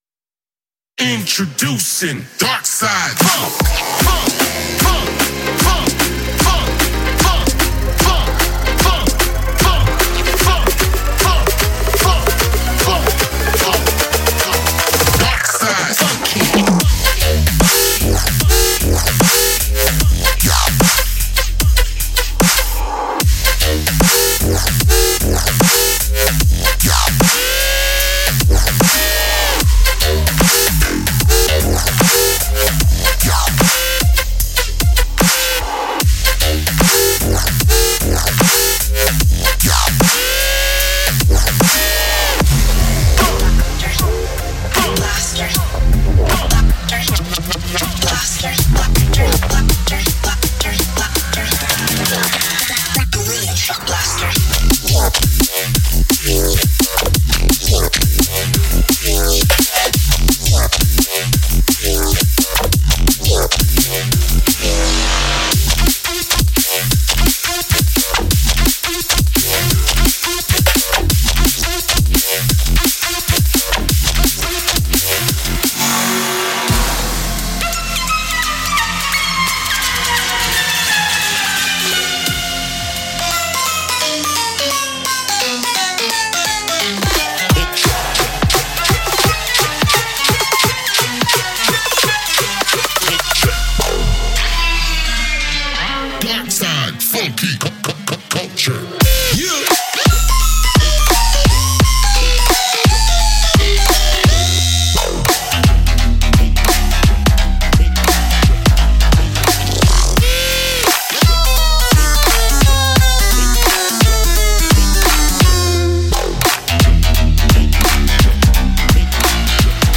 Dubstep
受一些我们最喜欢的科幻电影的启发，它使您身临其境，沉浸于沉重的低音，灼热的中档音域和变形纹理中。
•70个未来派低音音色